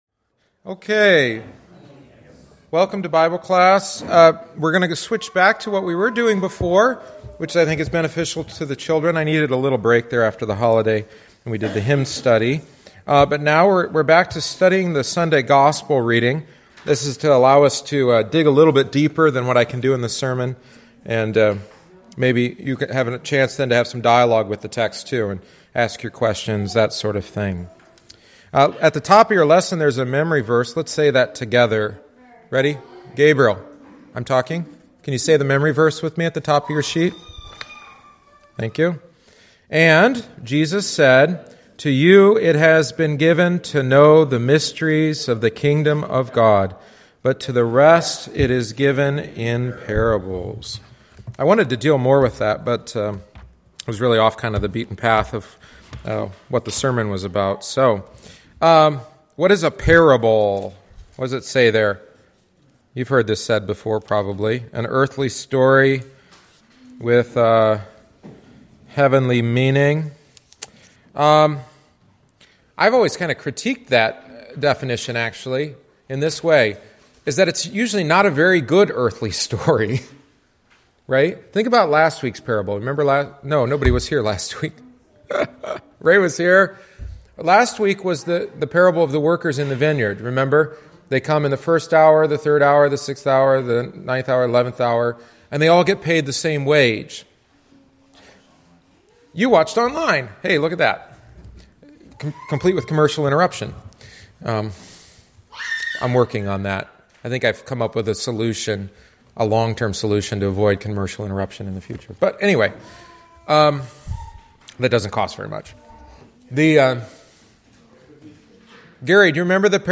Catechesis on the Sower and the Seed – Luke 8:4-15